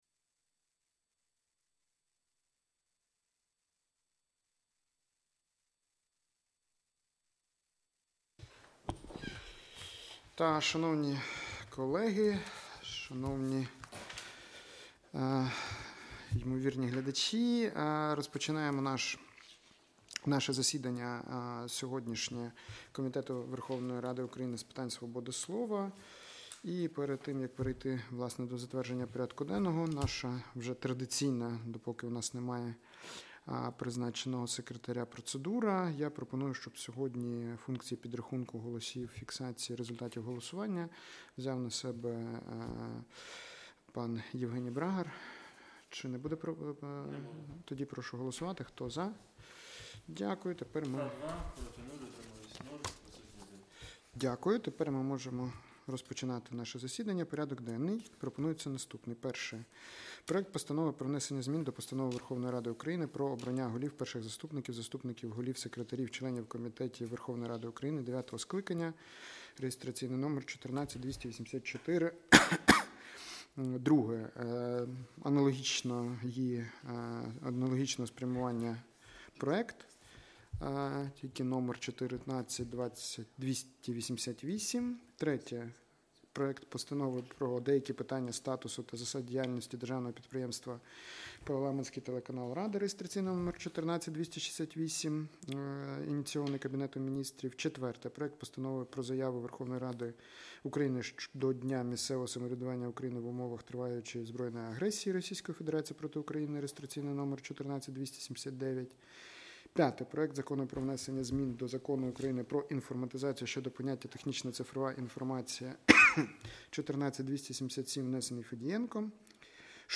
Аудіозапис засідання Комітету від 16 грудня 2025р.